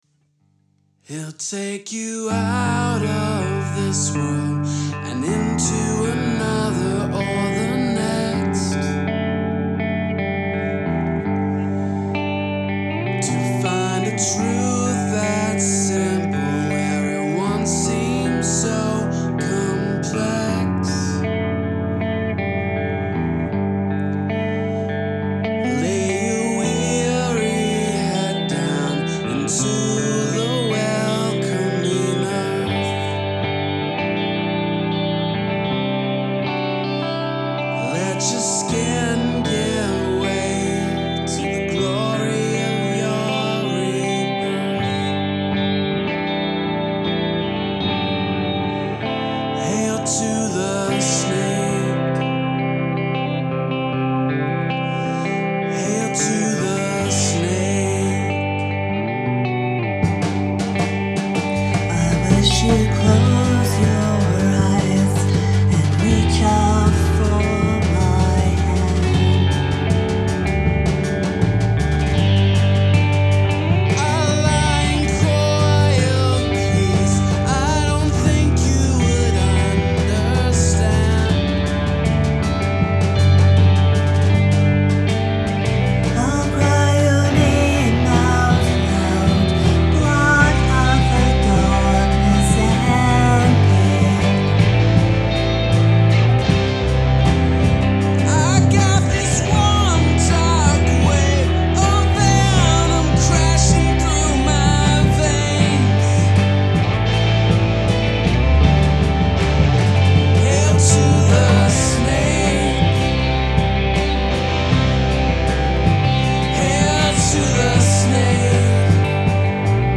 Collaboration with someone's mom